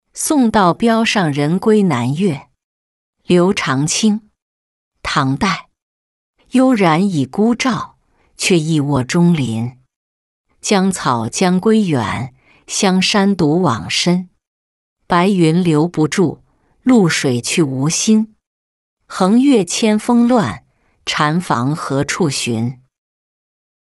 送道标上人归南岳-音频朗读